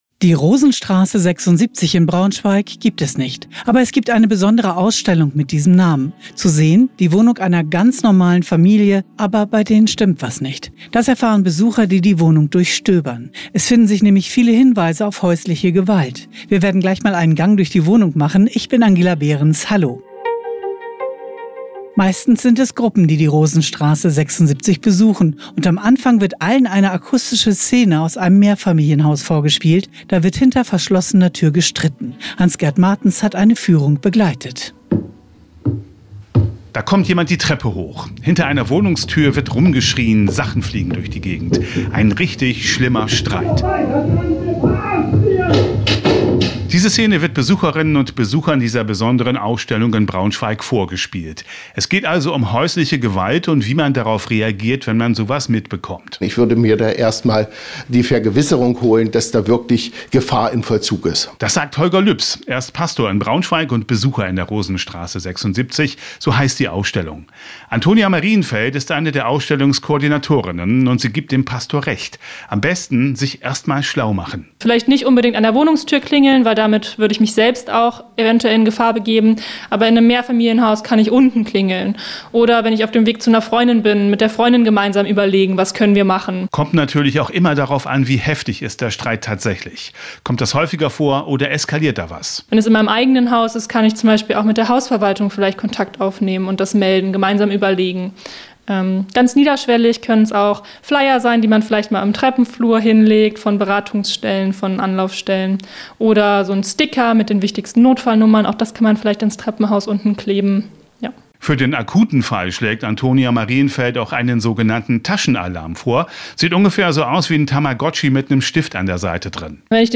In dem Beitrag geht es um eine interaktive Ausstellung, die dieses Thema sichtbar macht - mit Stimmen von Betroffenen, Hintergrundinfos und Momenten der Stille, des Innehaltens.